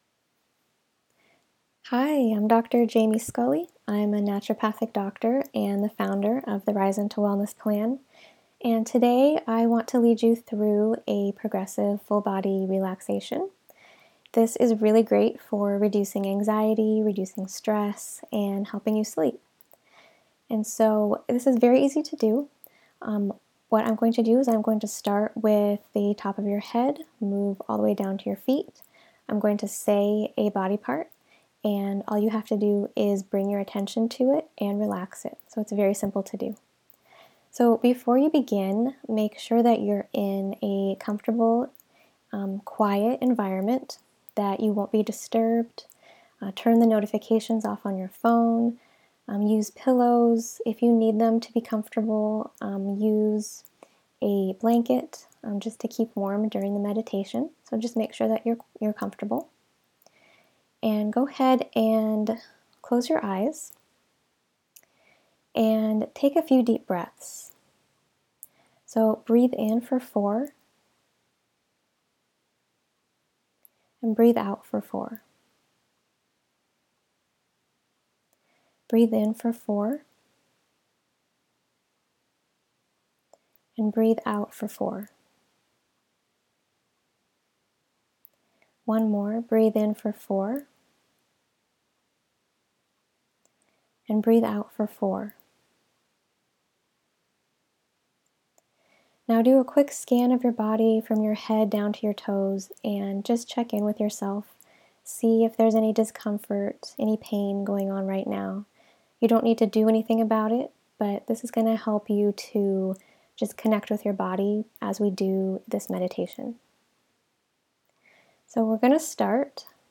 Doing activities like these help your nervous system reset and relax. I've recorded a 10 minute guided relaxation here or see video below.